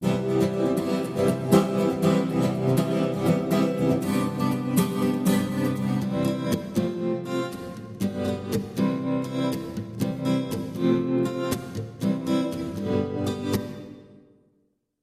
Digitales Effektmodul mit FV-1 Chip. 8 Digitale Effekte.
Tremolo+reverb
Tremolo+reverb.mp3